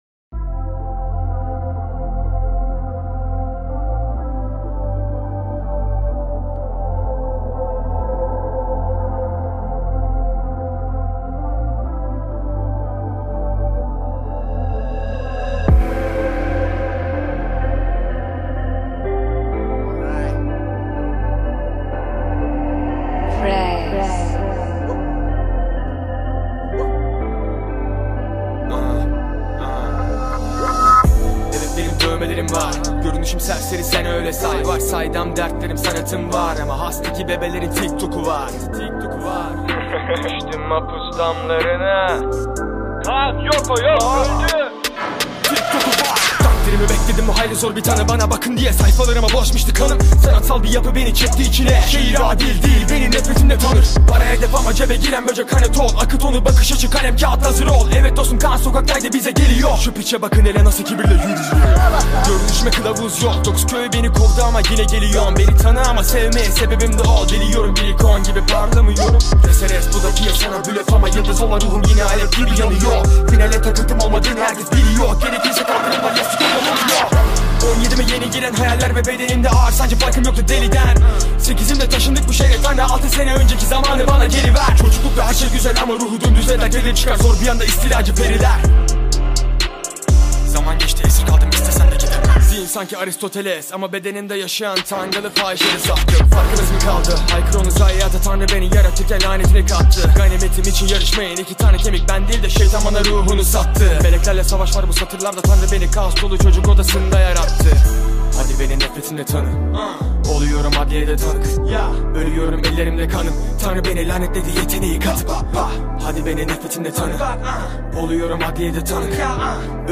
Жанр: Турецкая музыка